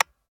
Home gmod sound weapons mpapa5
weap_mpapa5_disconnector_plr_01.ogg